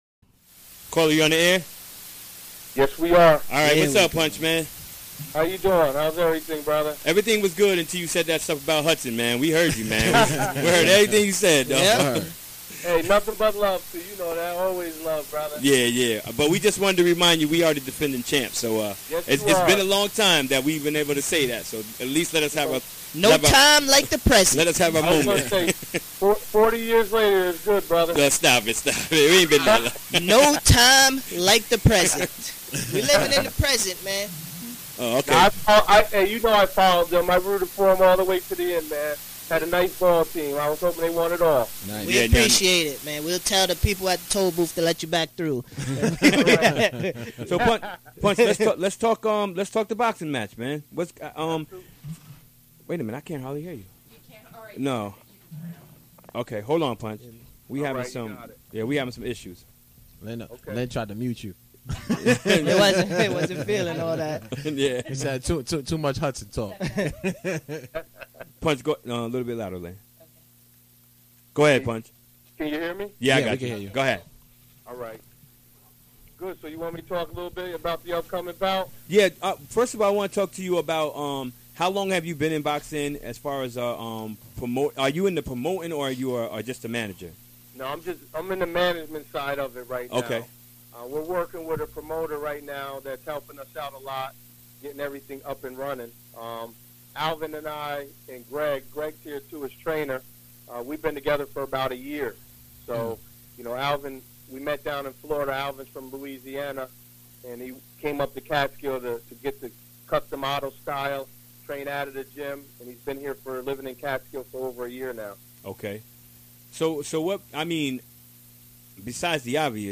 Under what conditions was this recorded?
Recorded during the WGXC Afternoon Show.